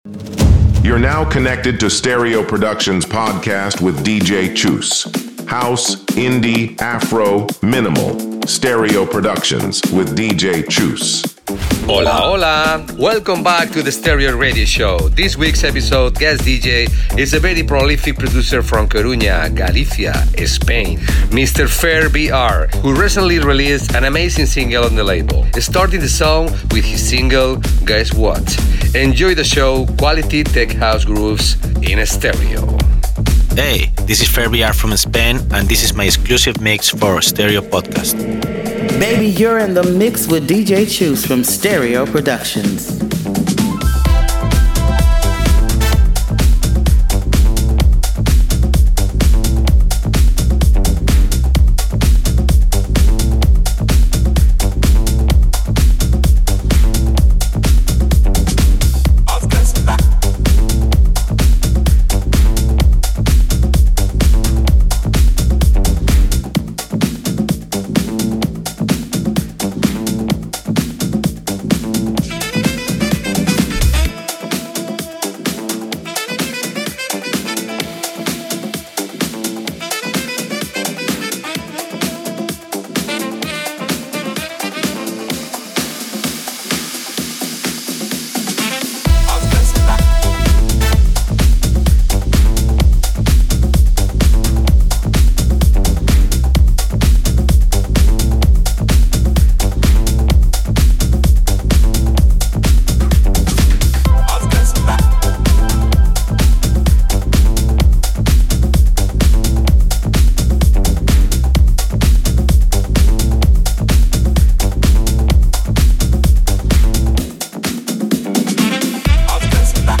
deep, rolling, and made for the dancefloor